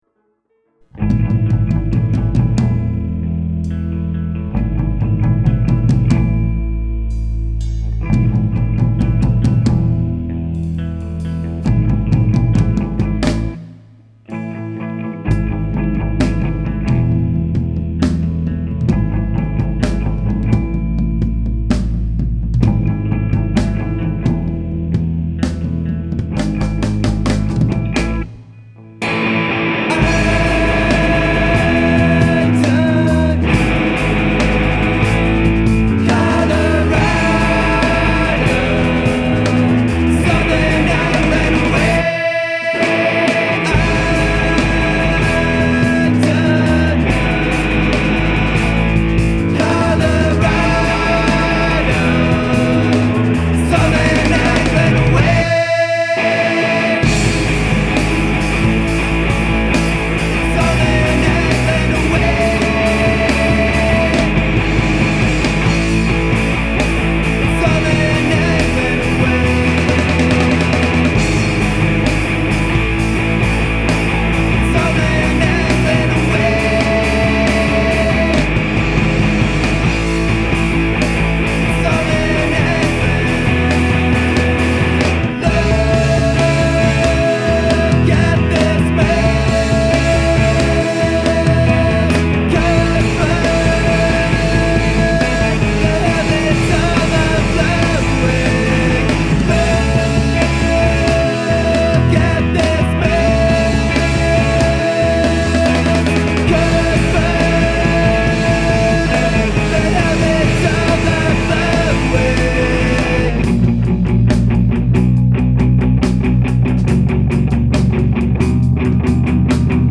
Vocals
Guitar
Bass
Drums
Filed under: Emo